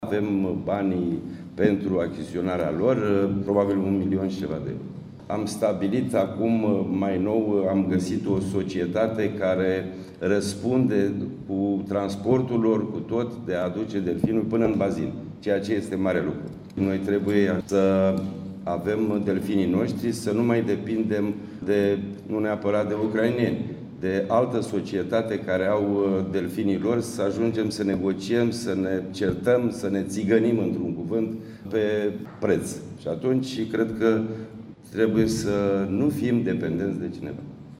Președintele Consiliului Județean Constanța, Florin Mitroi, spune că există banii necesari cumpărării delfinilor și a fost găsită și firma care se poate ocupa de transportul acestora.